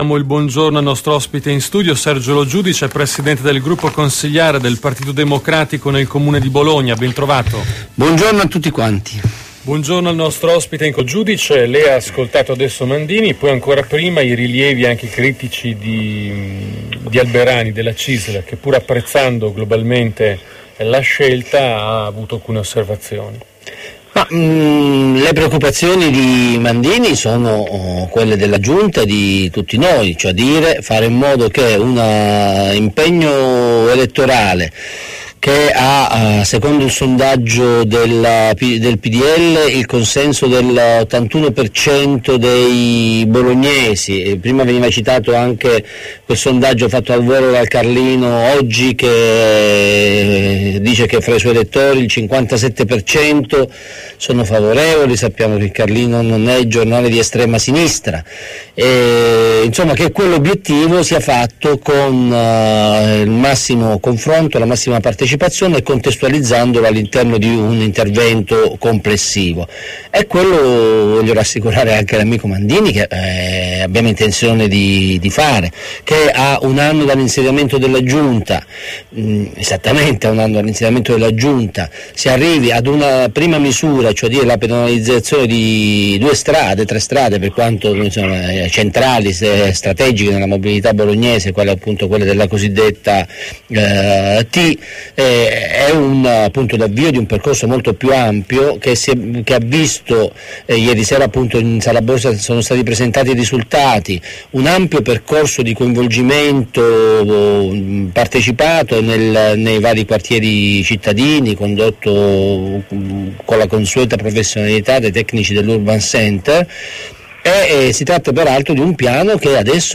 Intervista del capogruppo Pd Sergio Lo Giudice a Radio Tau (4 aprile 2012)